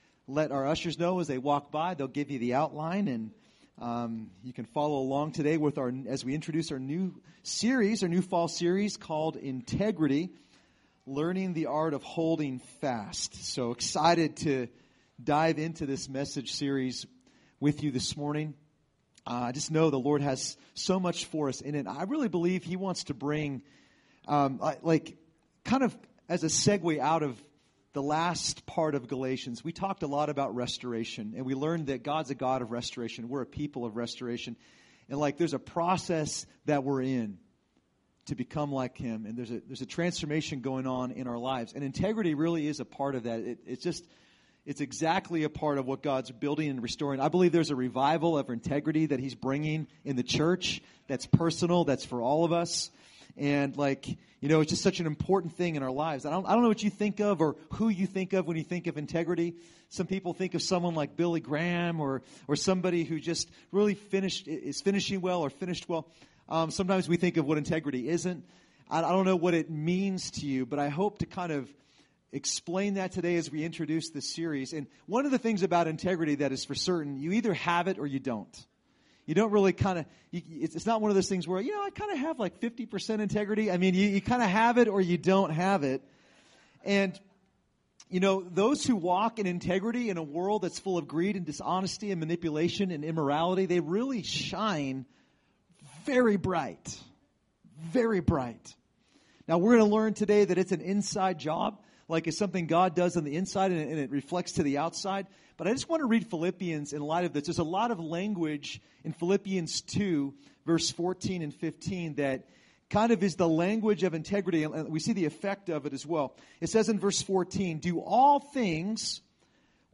Recorded at New Life Christian Center, Sunday, Oct 23, 2016 at 11 AM.